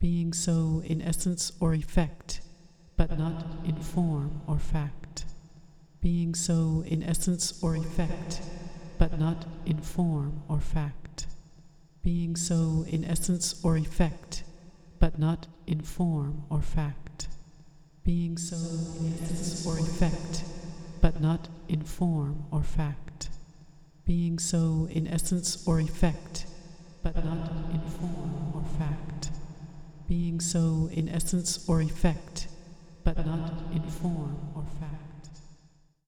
Listening back, we hear how the Reverb and Pan are independently controlled by the data.
TSV_EventValues_Verb-Pan.mp3